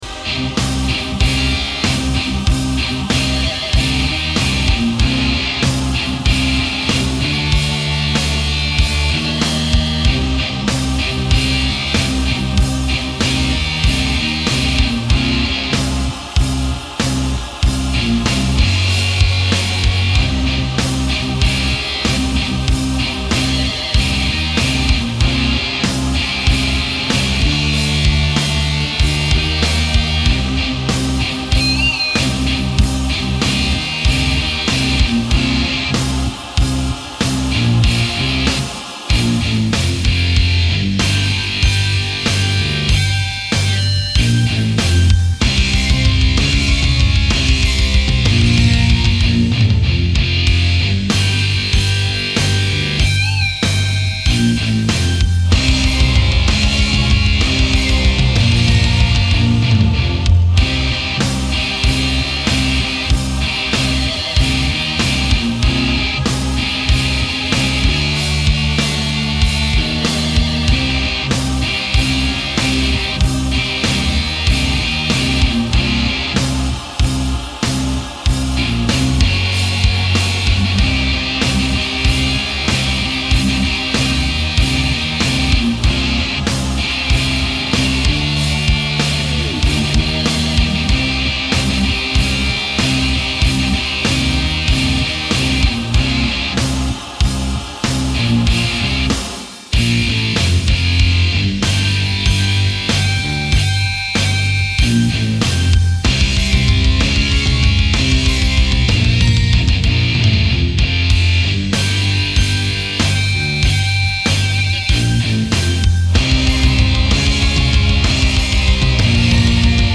Beim Rhythmus-Teil hab ich vor allem Bendings und Obertöne verwendet, und stellenweise auch natürlich Vibrato.
Anhänge Rhythm.mp3 Rhythm.mp3 4,6 MB · Aufrufe: 881 Solo.mp3 Solo.mp3 3,5 MB · Aufrufe: 867